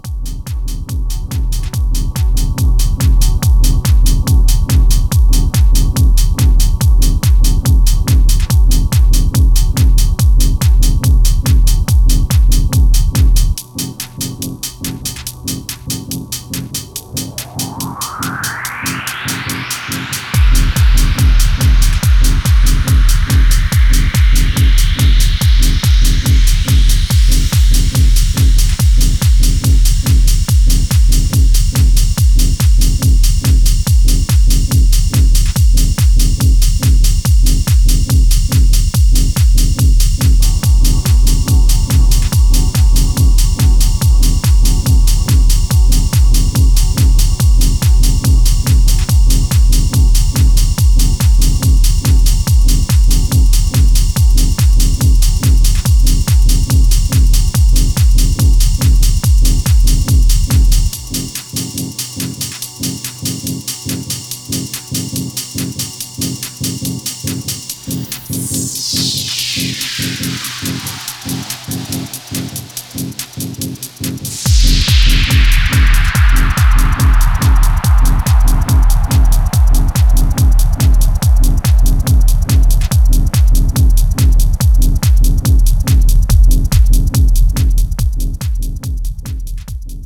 圧の強いサブベースを伴って疾走するキックの迫力がピークタイムにふさわしい